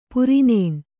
♪ puri nēṇ